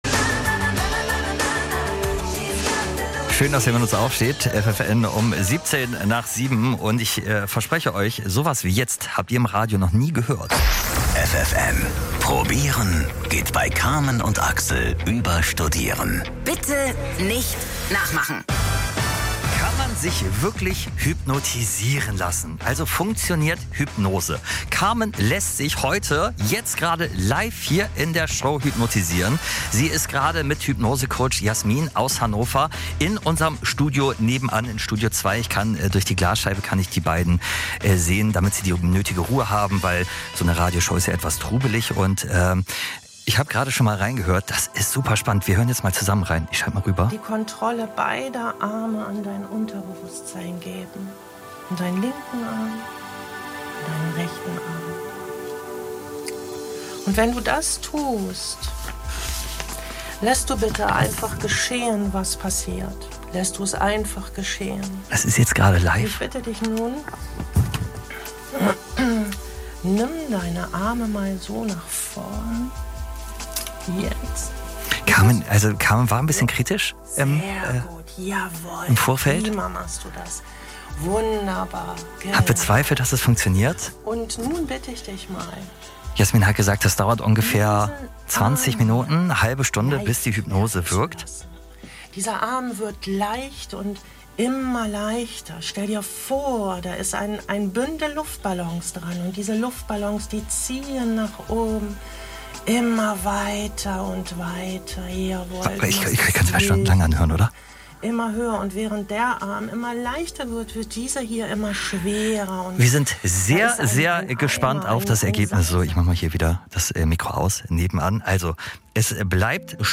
Ich hatte mein erstes Interview bei Radio FFN – eine absolute Premiere für mich und unglaublich spannend!